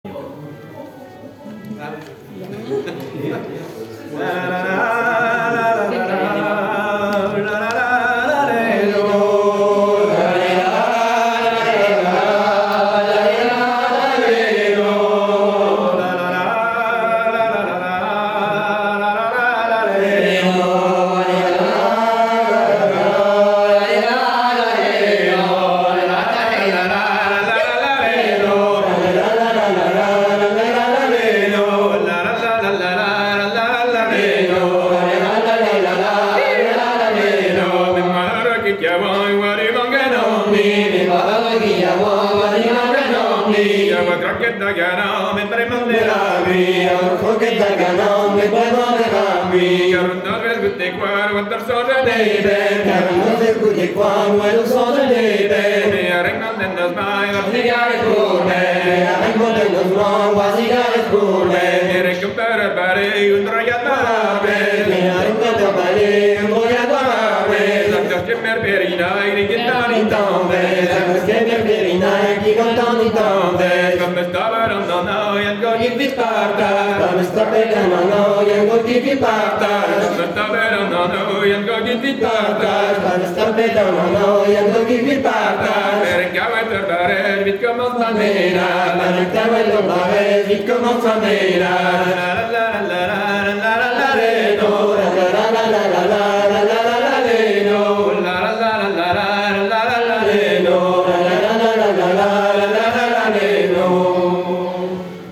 Gavottes Ton simple